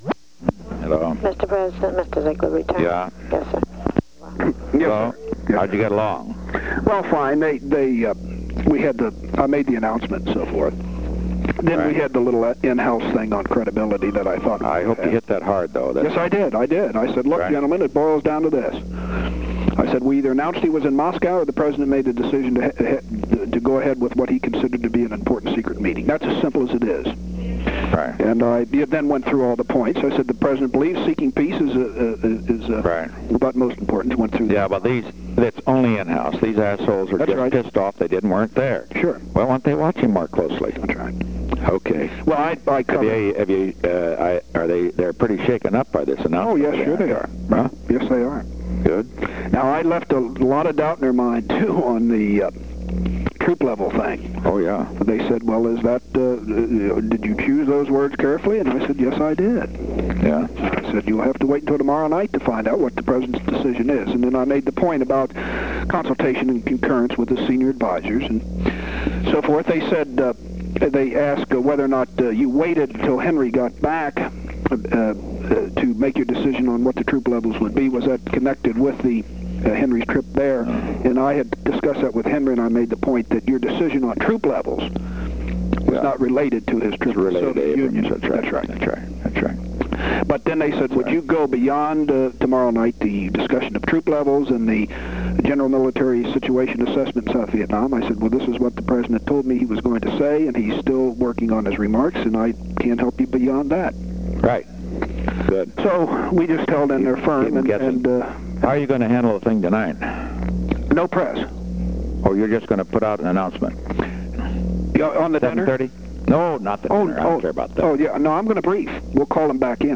On April 25, 1972, President Richard M. Nixon and Ronald L. Ziegler talked on the telephone from 4:43 pm to 4:45 pm. The White House Telephone taping system captured this recording, which is known as Conversation 023-075 of the White House Tapes.
Location: White House Telephone